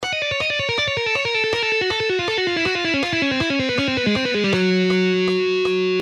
Cascading style guitar licks
Lesson 3: Legato Exercise In Cascade -Dimebag Darrell Style
Here its in Oringal Speed:
7.-Legato-Exercise-In-Cascade-Dimebag-Darrell-Style.mp3